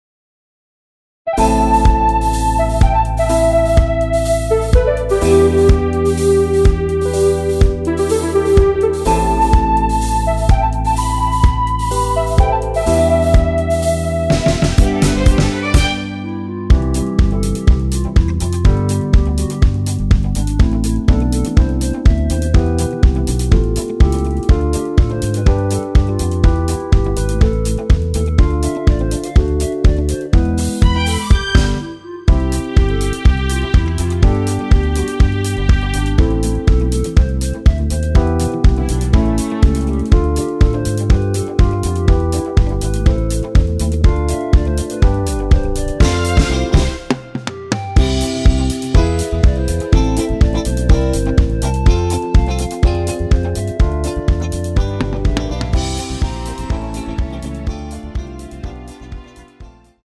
원키 멜로디 포함된 MR입니다.
앞부분30초, 뒷부분30초씩 편집해서 올려 드리고 있습니다.